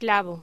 Locución: Clavo
voz